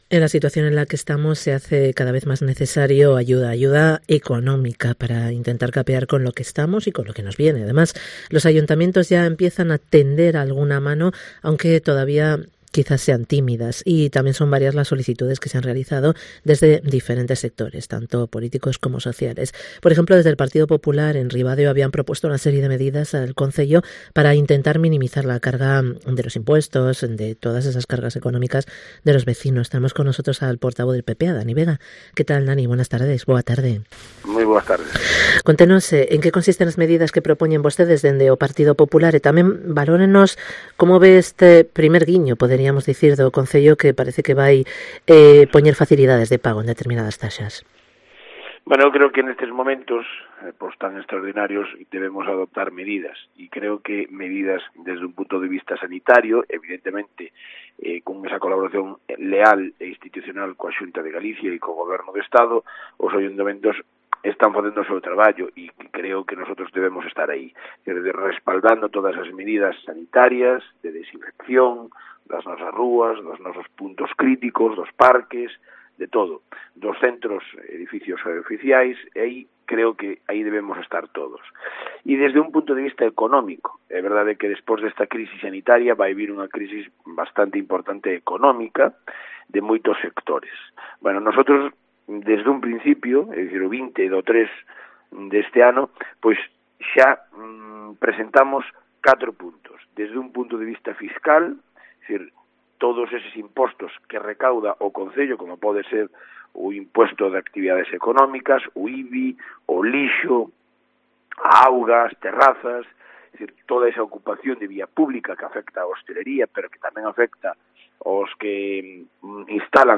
Entrevista con DANIEL VEGA, portavoz del PP en el Ayuntamiento de Ribadeo